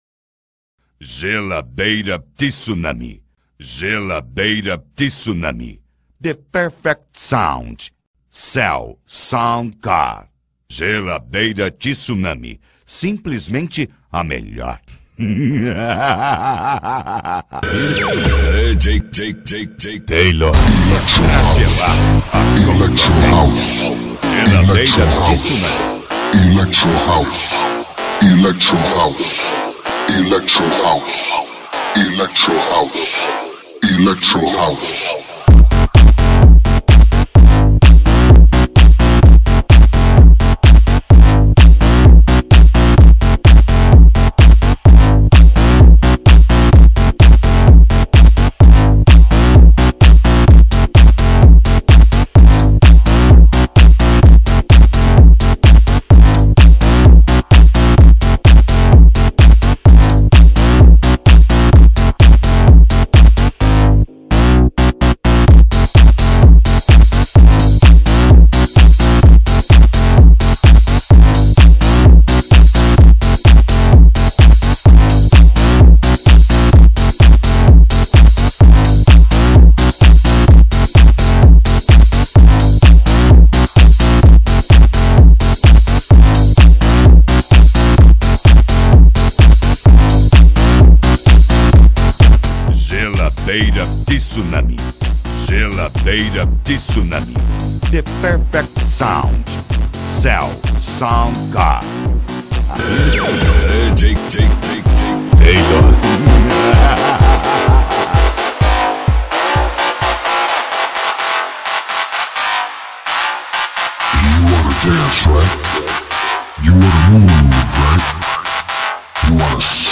Remix.